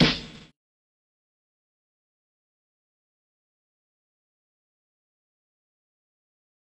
snare 9 .wav